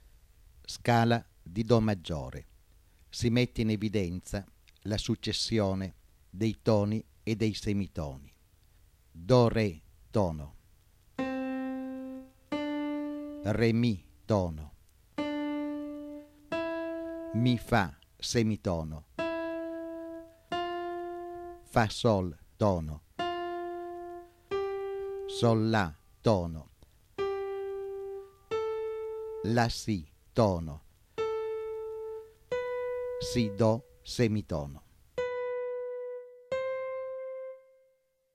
01.  Ascolto della scala, sopra riportata, che si chiama: scala di Do Maggiore.
01_Scala_di_Do_M.wma